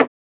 Batmans DarkSoul Perc 5.wav